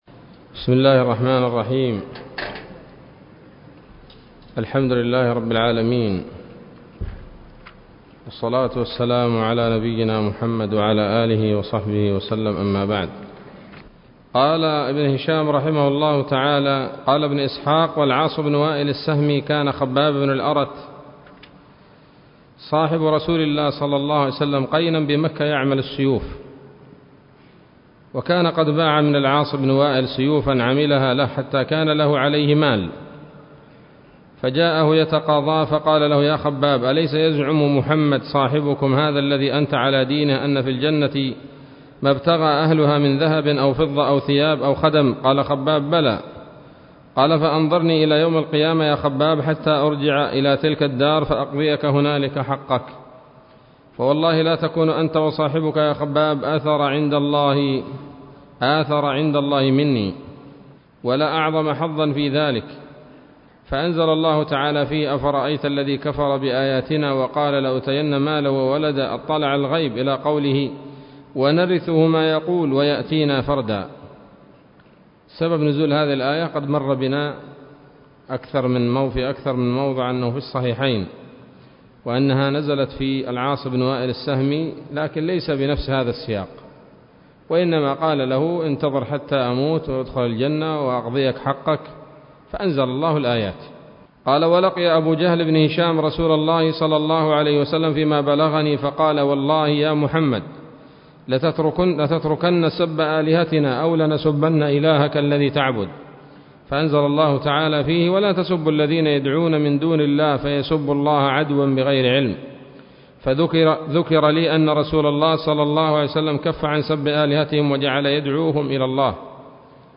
الدرس الثامن والثلاثون من التعليق على كتاب السيرة النبوية لابن هشام